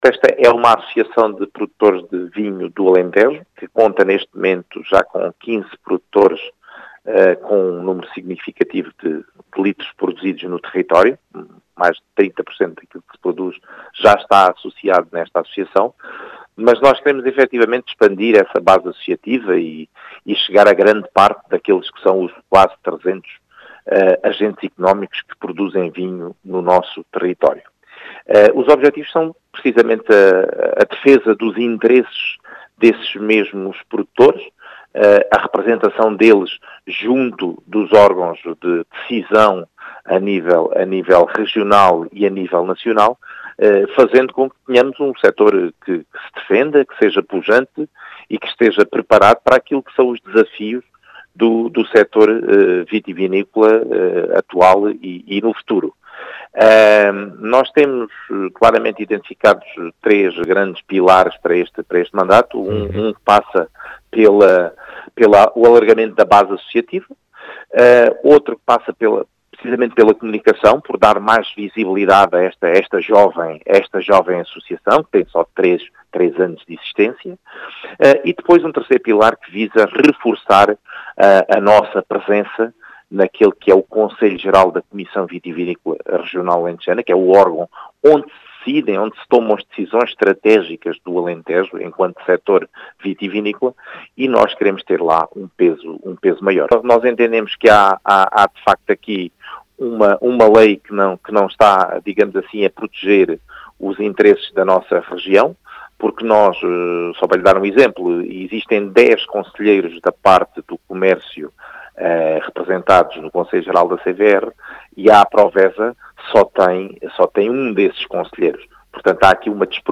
As explicações foram deixadas na Rádio Vidigueira